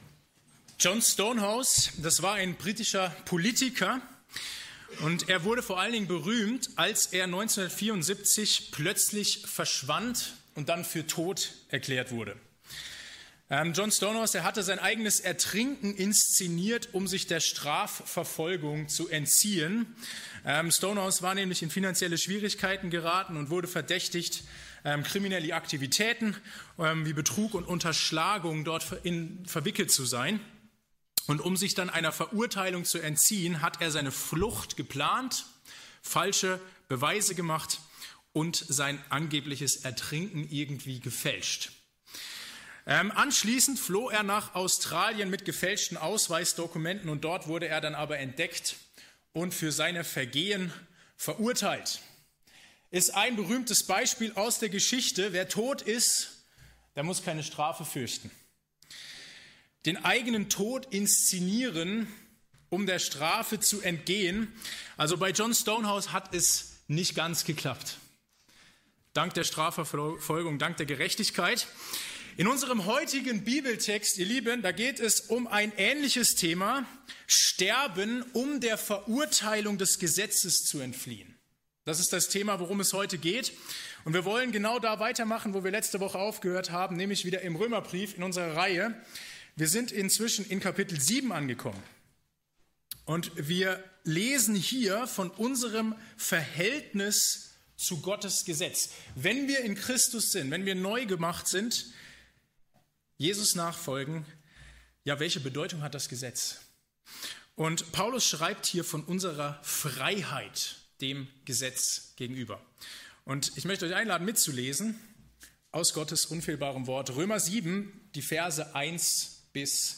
Kategorie: Gottesdienst